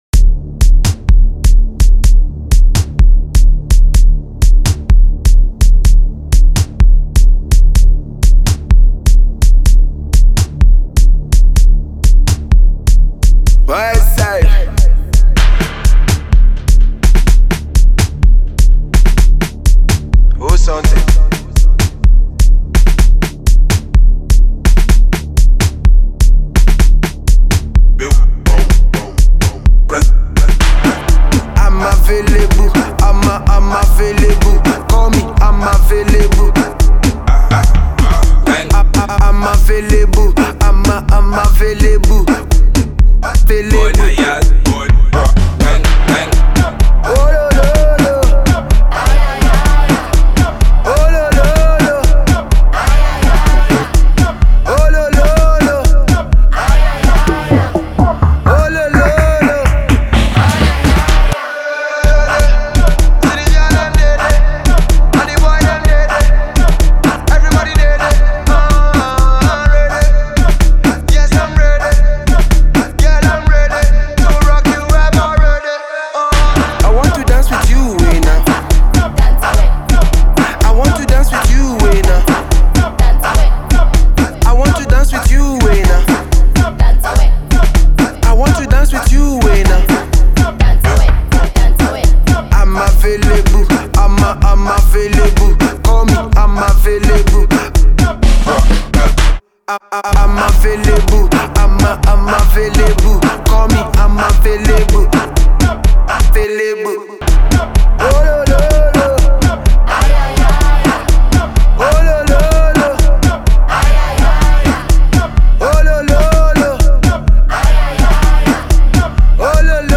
South African house subgenre Qgom
The heavy drum sound emerged from the townships of Durban.
with a simple catchy chorus and a contagious beat